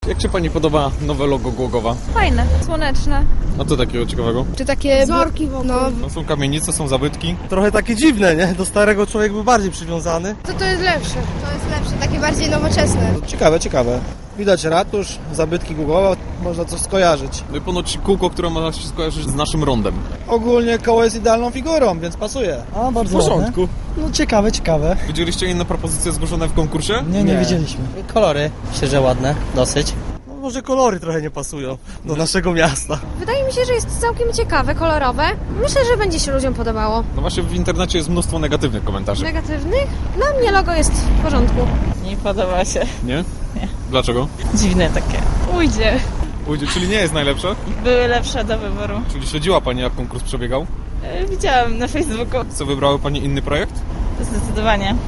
Prezydent Głogowa Rafael Rokaszewicz wyjaśnia, że o plagiacie nie może być mowy.